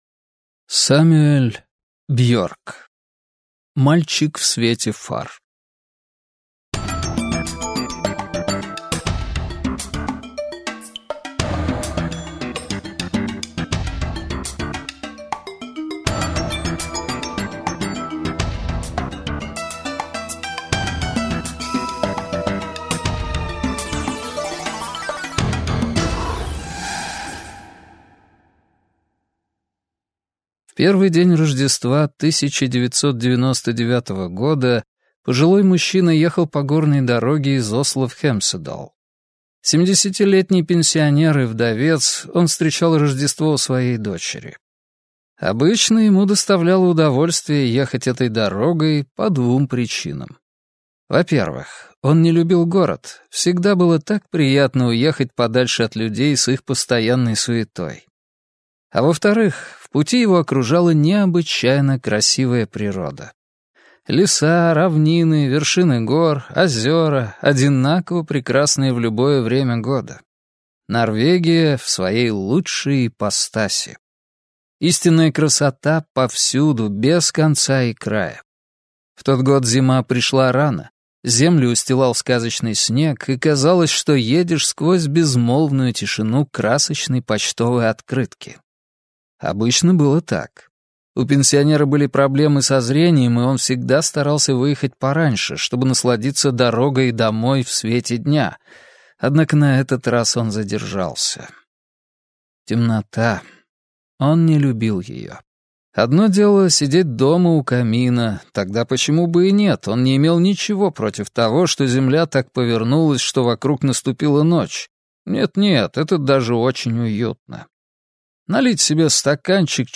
Аудиокнига Мальчик в свете фар - купить, скачать и слушать онлайн | КнигоПоиск